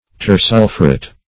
Search Result for " tersulphuret" : The Collaborative International Dictionary of English v.0.48: Tersulphuret \Ter*sul"phu*ret\, n. [Pref. ter- + sulphuret.]